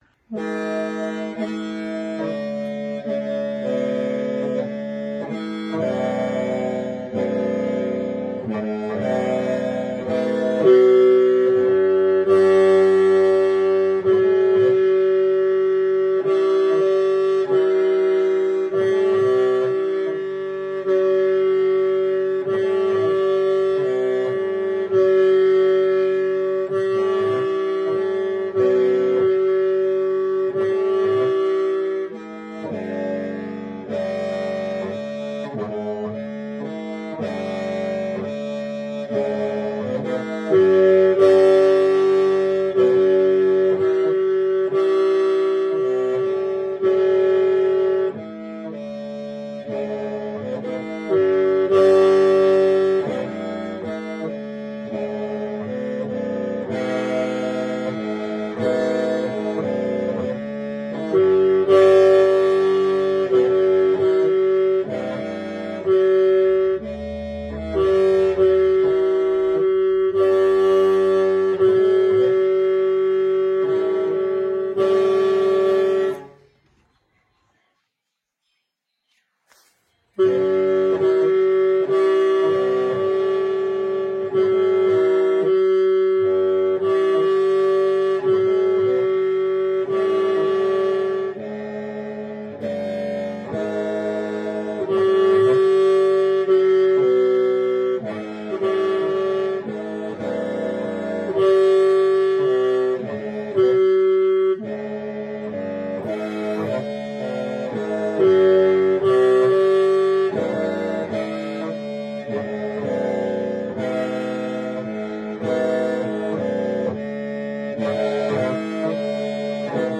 Qeej Beginner Basic Easy version
Qeej Basics Audio recording for reference
qeej_basic01.mp3